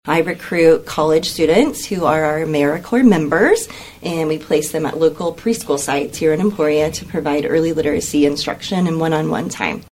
This is AmeriCorps week, and KVOE’s Morning Show had several guests from Emporia State University’s Teachers College to discuss the program and their involvement.